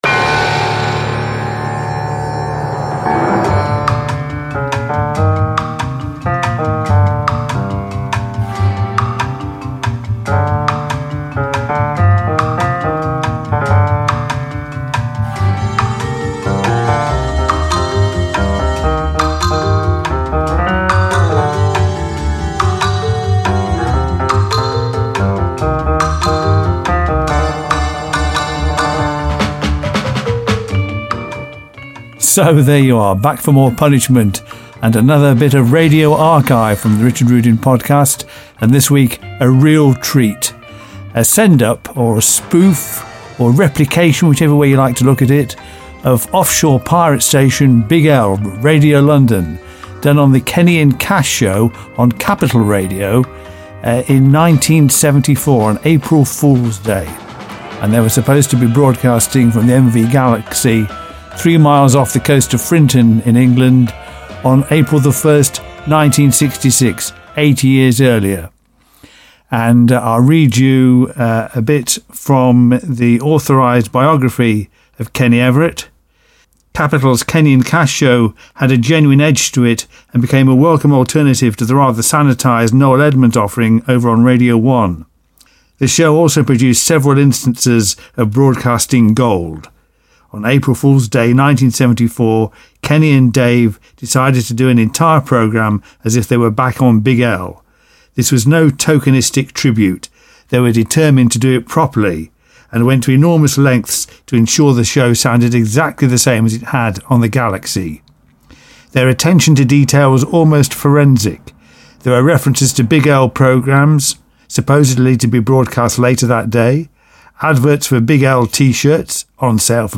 From April 1st, 1974: a bit of radio magic, as Kenny Everett and Dave Cash replicate offshore 1960s pirate station 'Wonderful' Radio London - Big L.